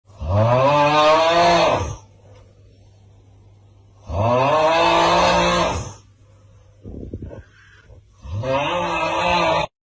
Tiktok Snoring Sound Effect Free Download
Tiktok Snoring